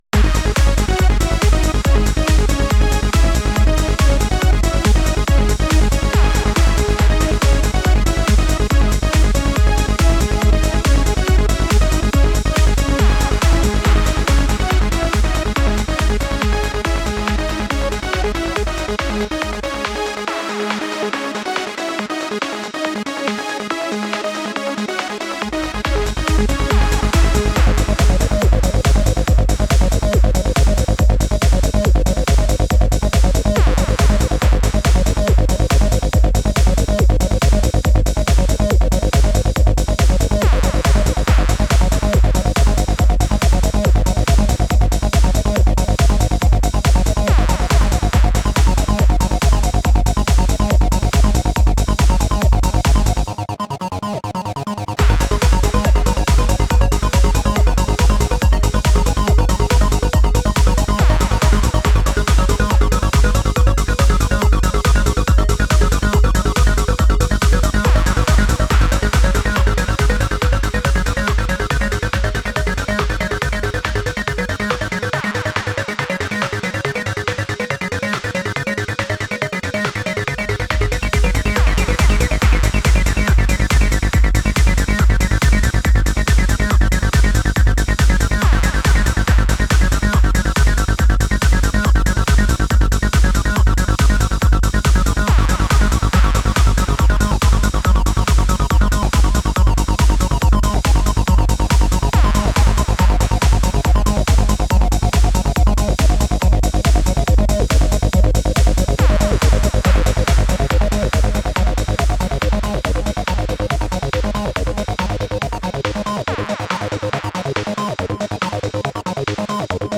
AN IMPRESSIVE COLLECTION OF PSYTRANCE SOUNDS